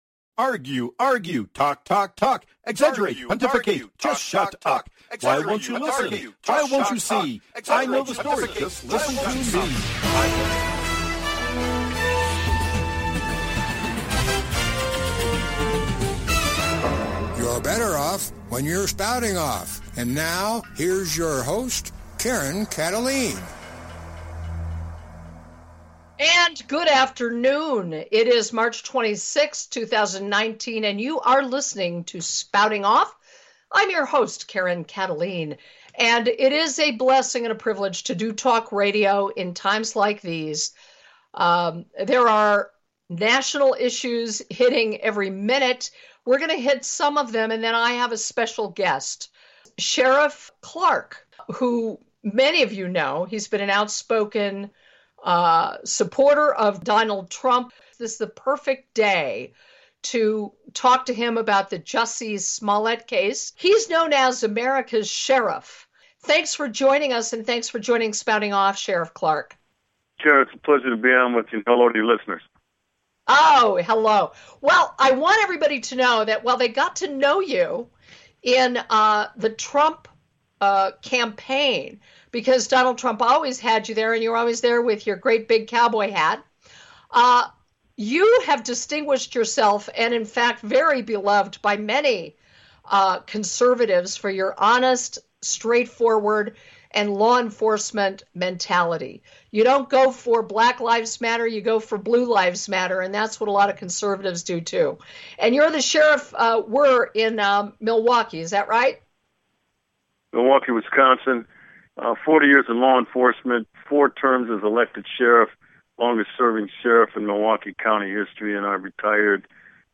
Guest, Sheriff David Clarke, America's Sheriff